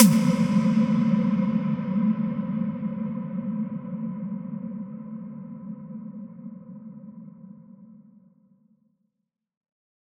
Big Drum Hit 14.wav